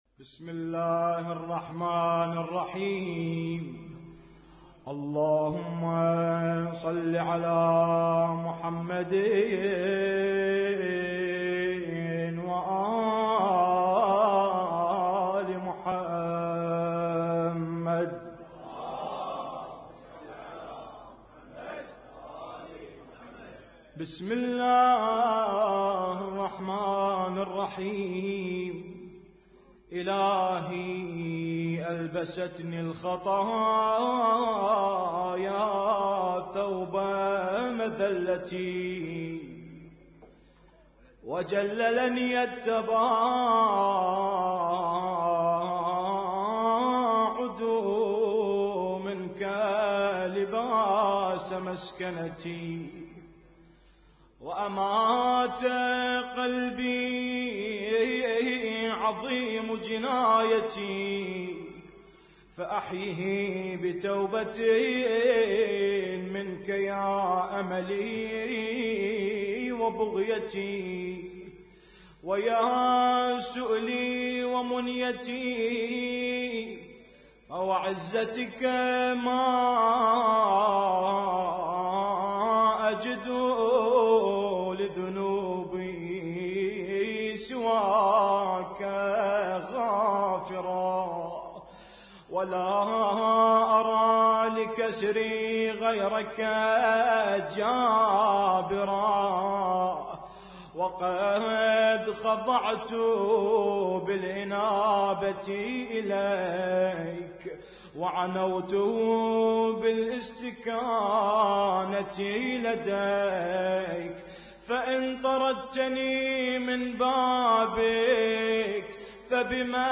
احياء ليلة 19 رمضان 1436
اسم التصنيف: المـكتبة الصــوتيه >> الادعية >> ادعية ليالي القدر